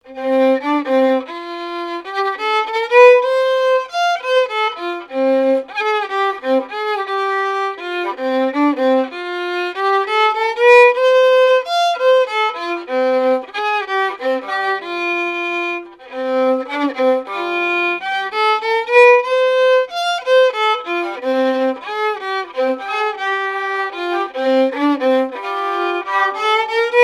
Marche nuptiale n° 2
circonstance : fiançaille, noce
marches de noces jouées aux Gueurnivelles
Pièce musicale inédite